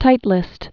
(tītlĭst, -l-ĭst)